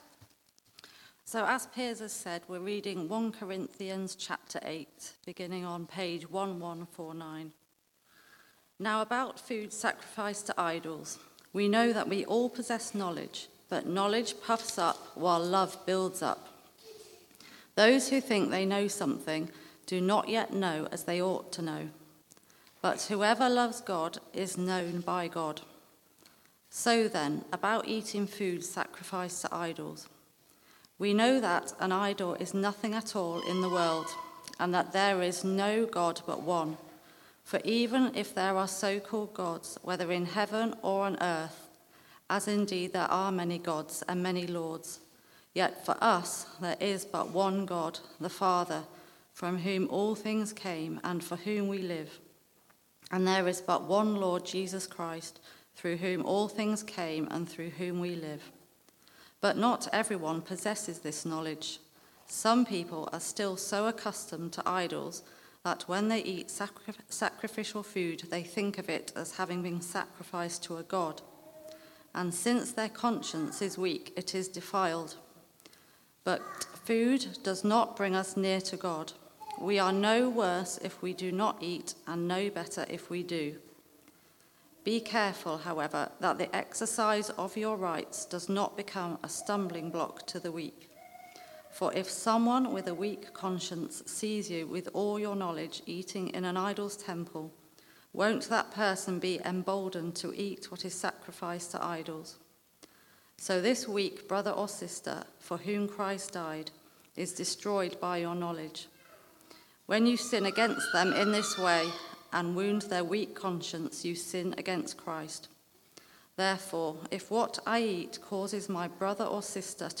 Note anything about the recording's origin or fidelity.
Church at the Green Sunday 4pm